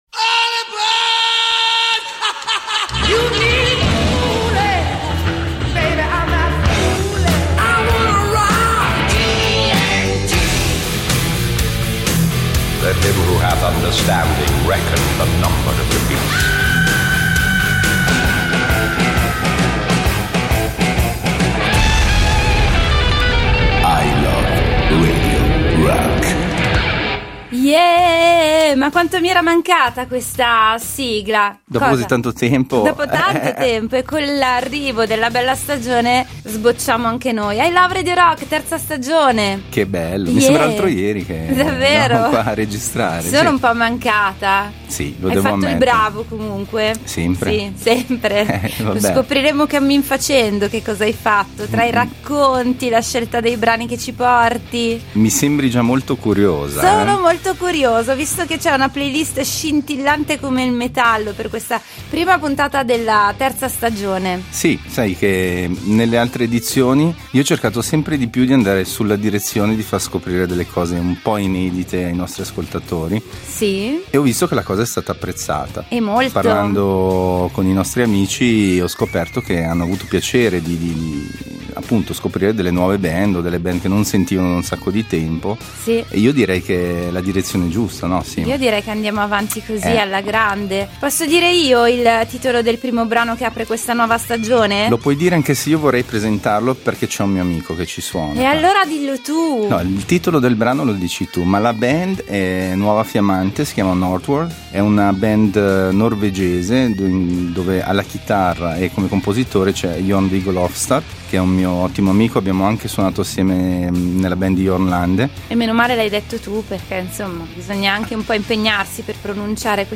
il programma dedicato alle sonorità hard rock e heavy metal che hanno fatto la storia. https